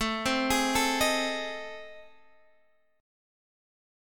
AmM7b5 chord